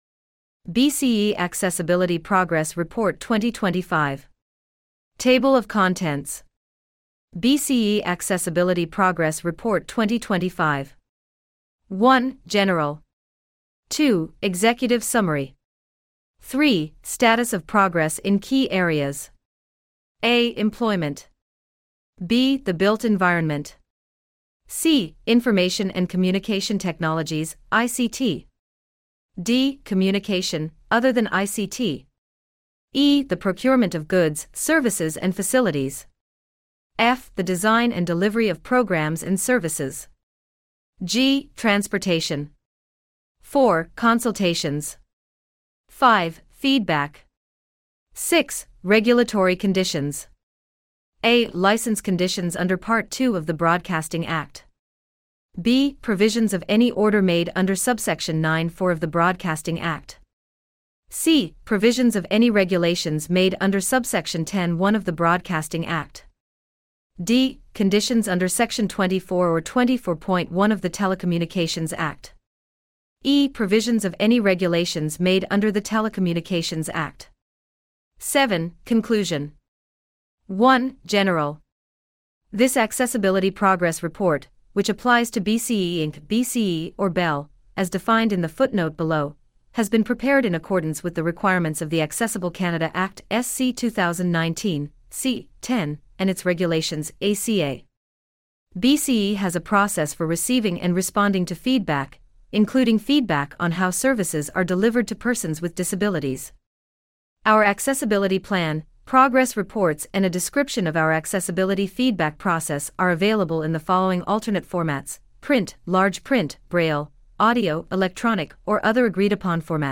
2025-bce-progress-report-audiobook.mp3